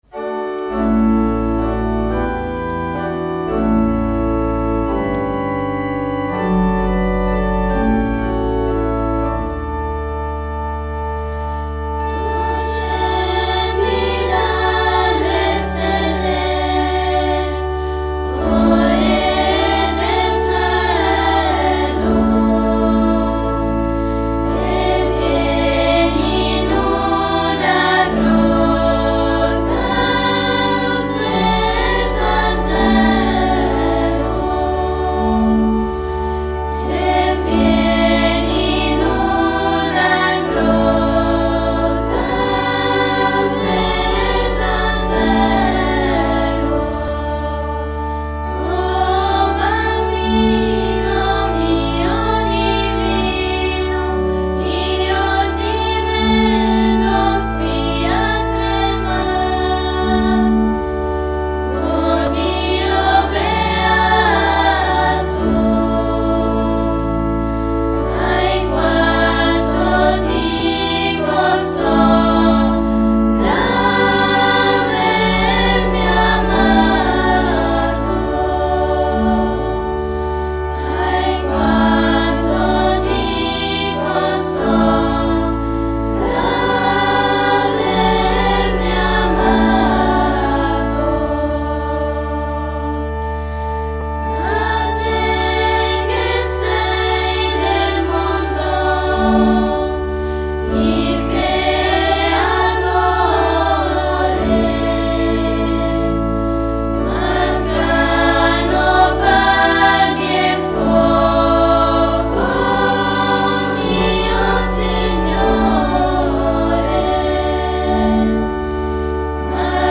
Una canzoncina di S. Alfonso
Ascolta e scarica la melodia (Coro dell’Annunziata di S. Agata dei Goti)